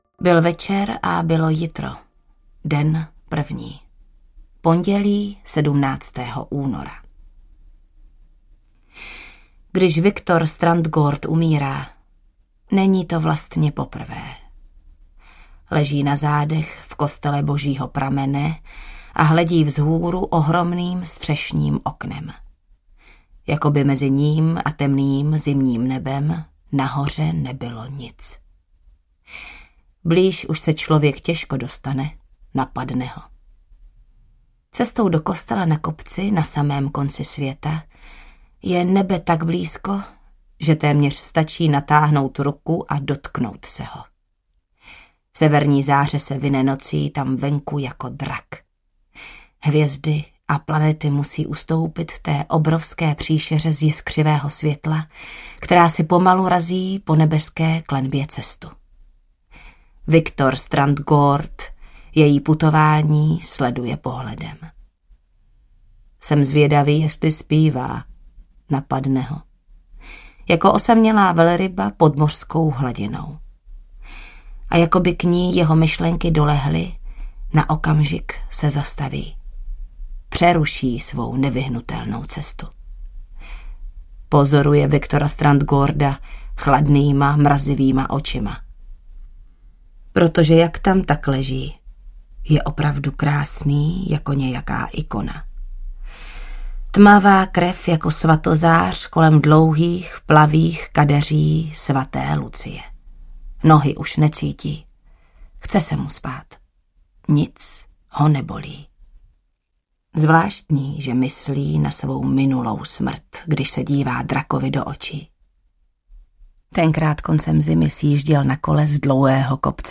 Sluneční bouře audiokniha
Ukázka z knihy
• InterpretJana Stryková
slunecni-boure-audiokniha